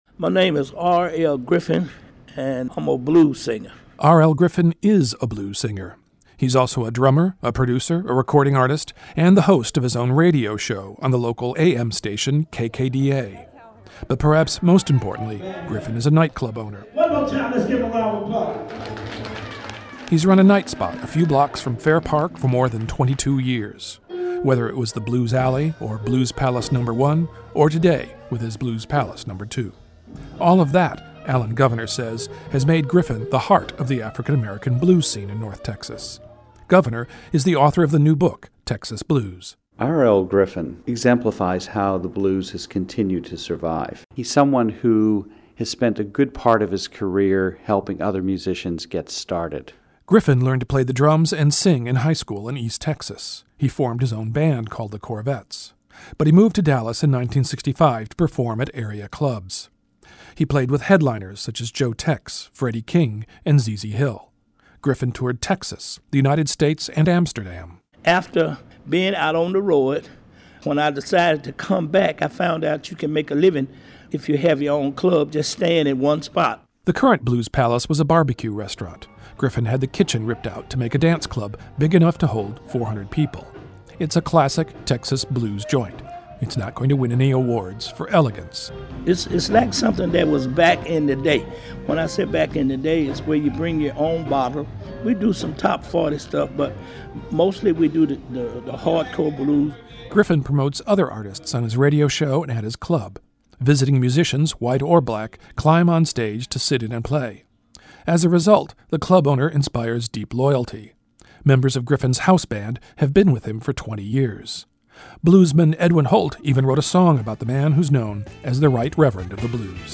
• KERA radio story:
[club noises and music start]